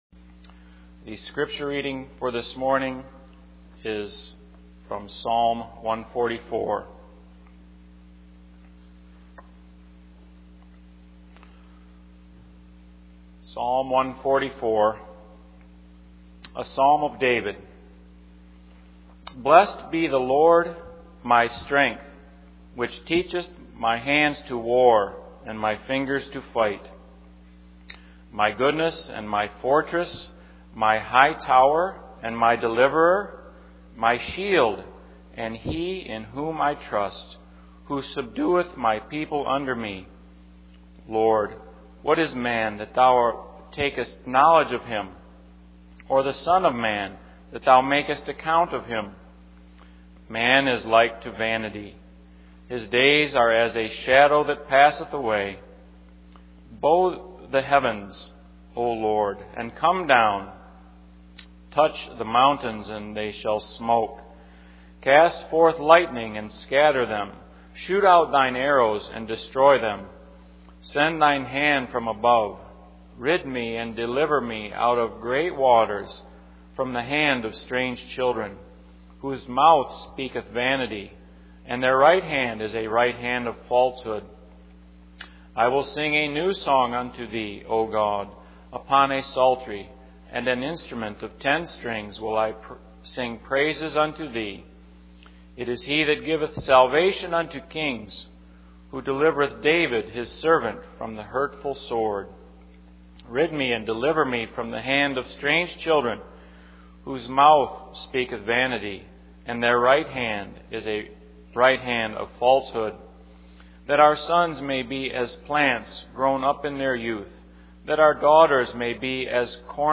The sermon transcript discusses the importance of finding true happiness and the misconceptions surrounding it. It emphasizes that true happiness comes from the grace of God and living a righteous and godly life.